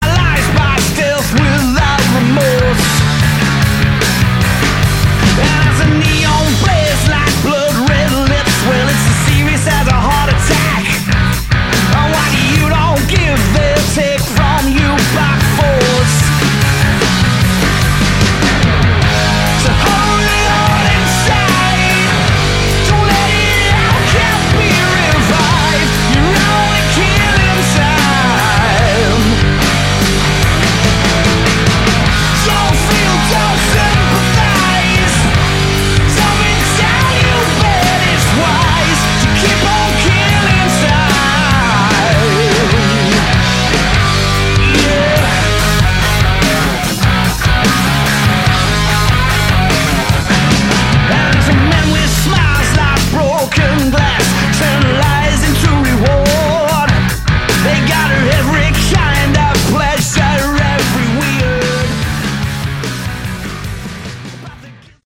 Category: Hard Rock
vocals, guitar